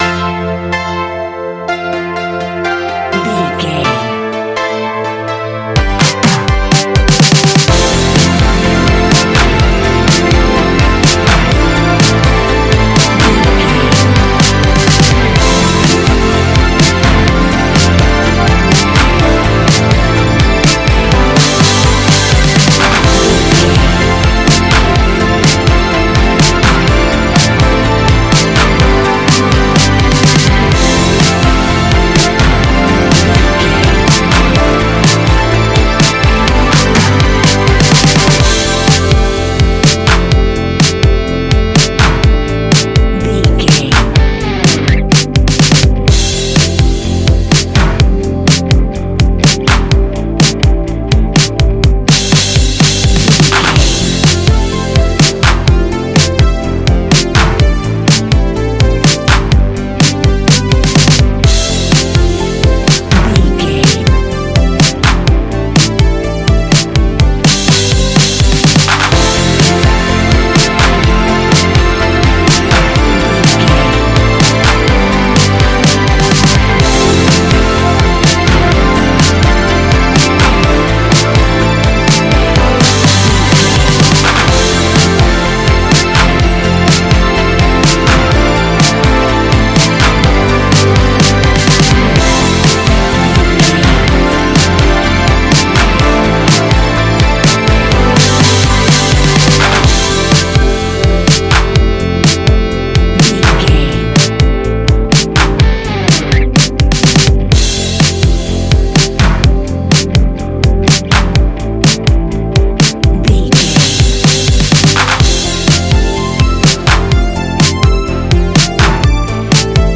Ionian/Major
ambient
electronic
new age
chill out
downtempo
synth
pads
drone